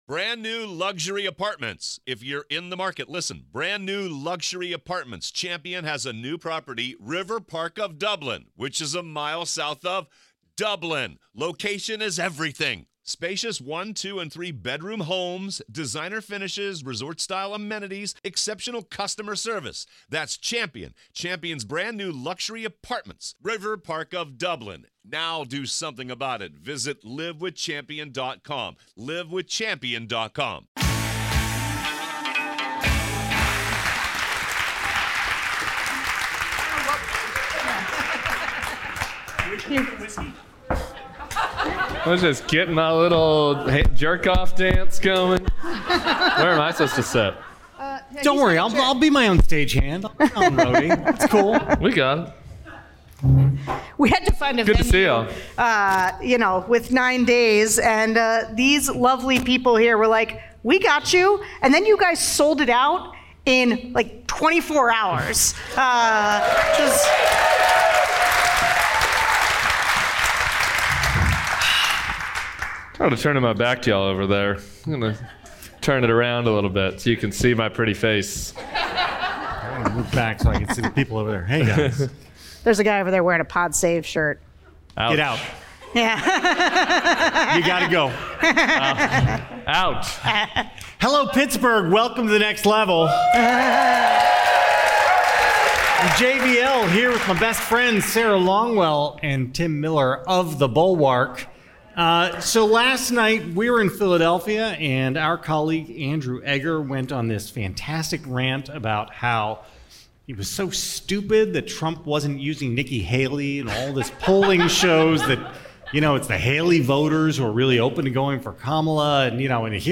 Live from Pittsburgh!